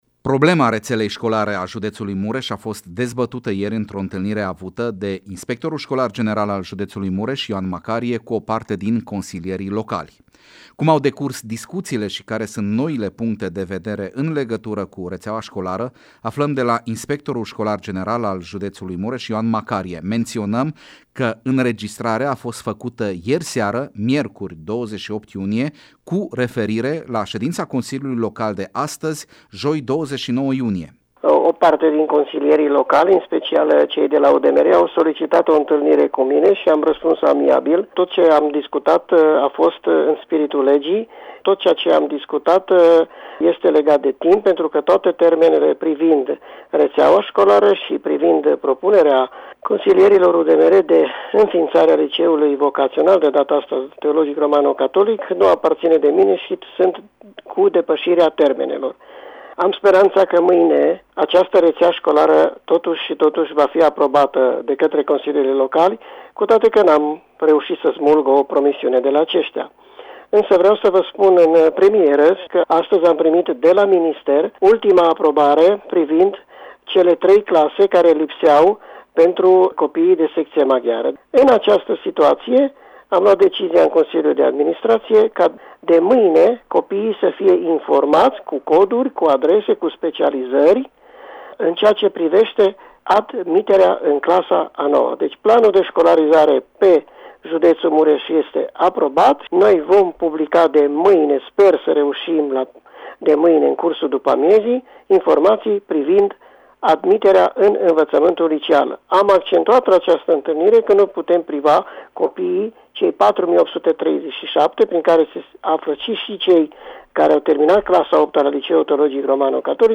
reportaj-retea-scolara.mp3